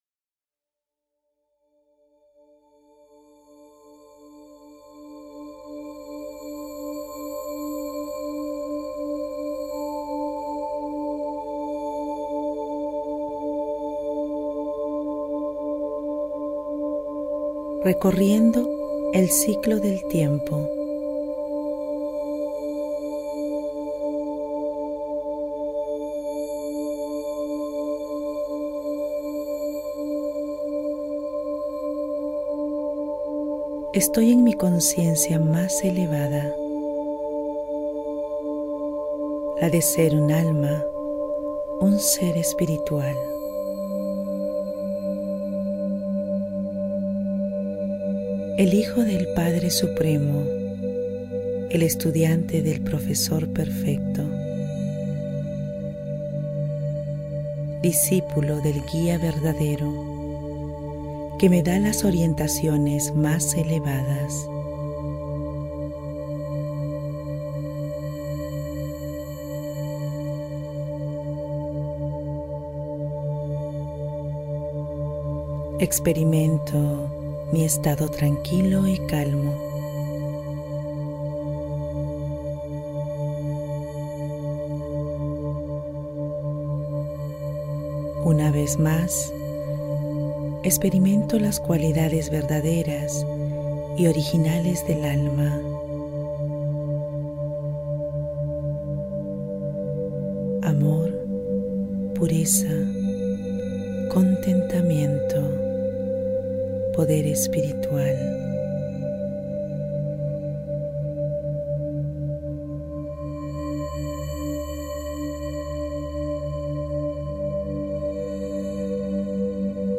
Genre Meditaciones Guiadas
audios-musica-meditaciones-guiadas